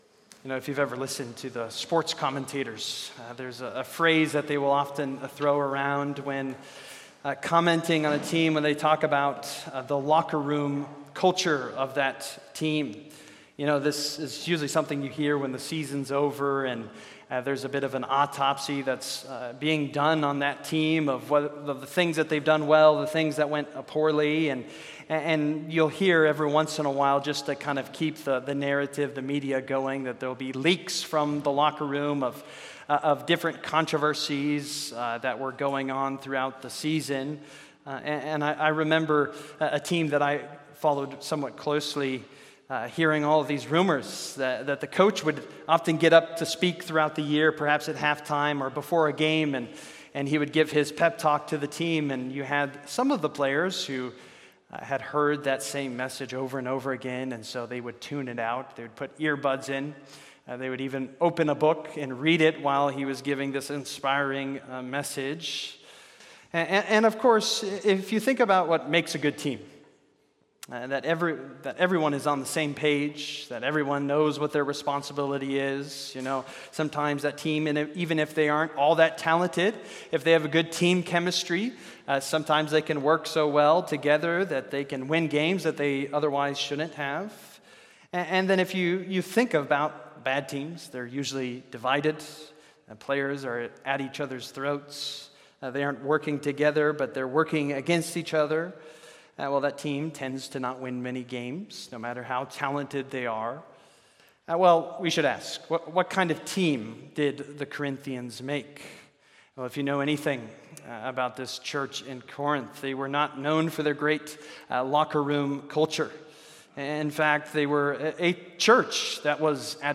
Service: Sunday Evening